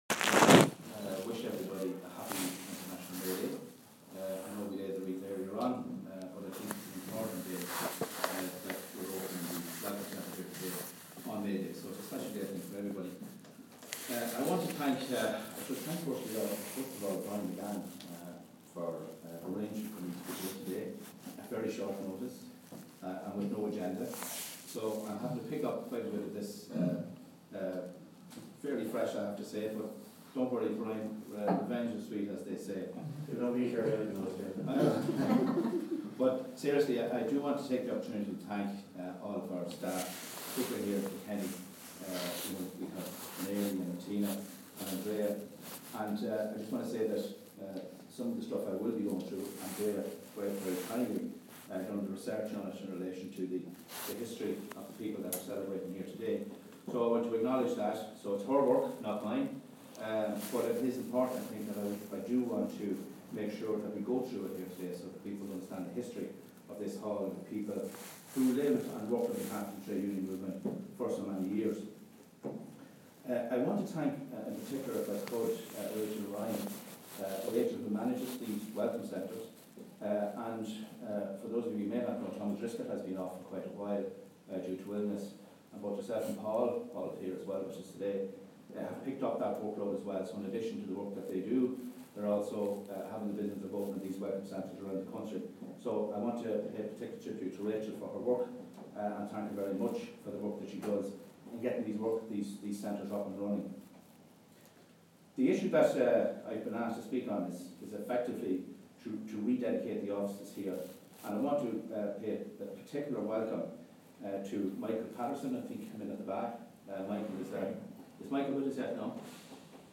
Launch of Welcome Centre Patterson-Boran Building Patrick Street Kilkenny.